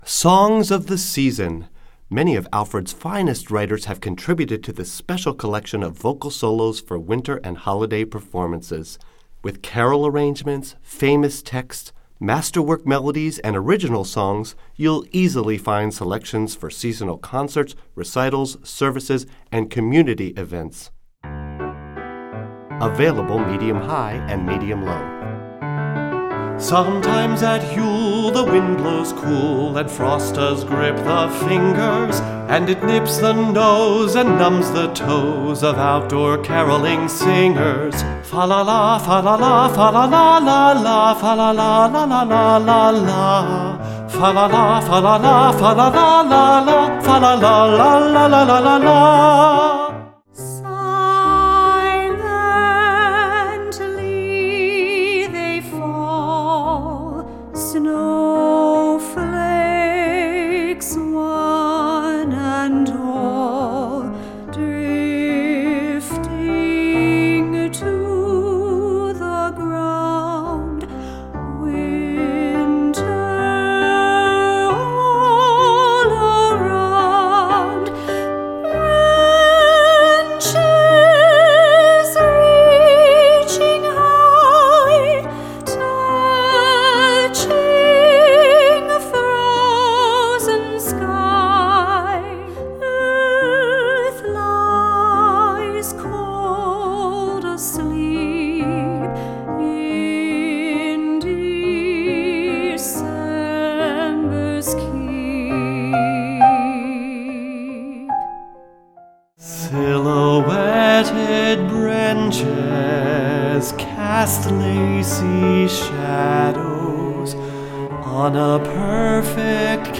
Voicing: Medium-Low Voice and Audio Access